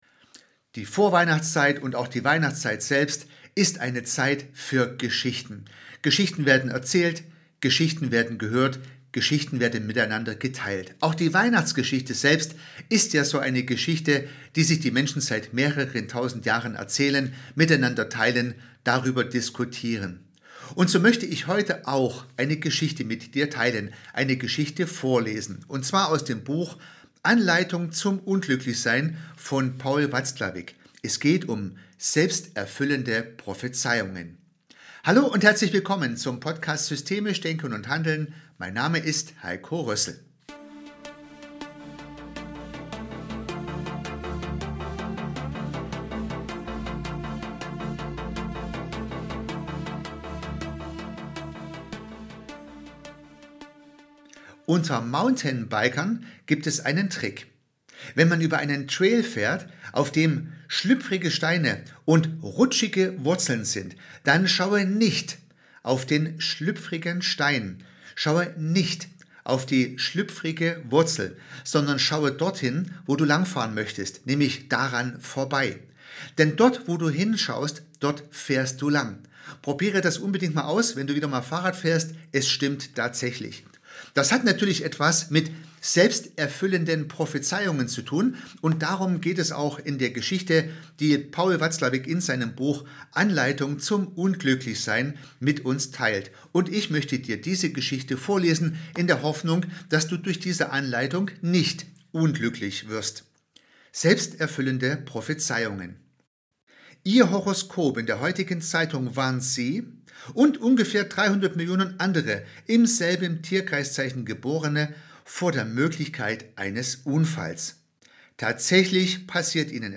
Genau um dieses Prinzip geht es in Paul Watzlawicks Geschichte aus Anleitung zum Unglücklichsein. Ich lese eine Passage über selbsterfüllende Prophezeiungen. Die Geschichte zeigt mit einem Augenzwinkern, wie Erwartungen, Warnungen und gut gemeinte Vermeidungsstrategien Wirklichkeit erzeugen – und warum Ursache und Wirkung dabei ununterscheidbar werden.